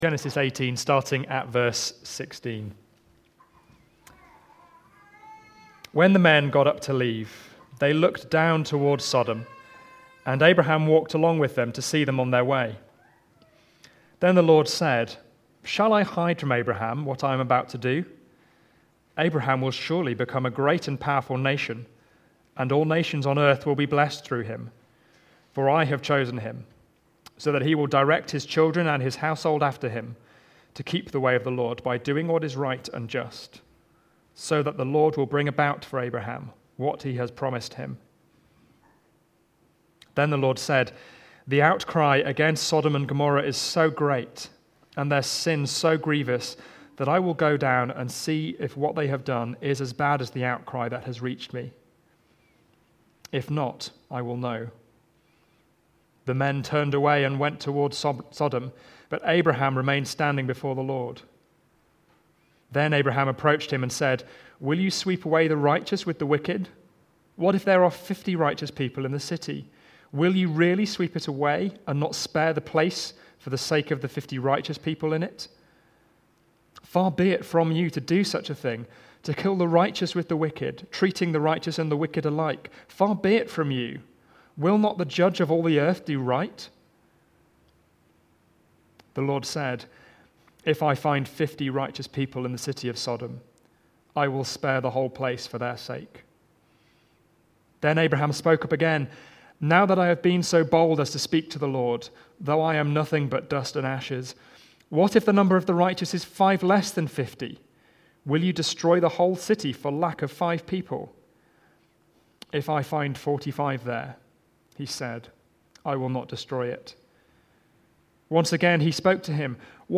Preaching
Pleading in Prayer (Genesis 18:16-33) from the series A Vision for 2020. Recorded at Woodstock Road Baptist Church on 15 March 2020.